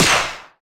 hitBaxter_Farthest.wav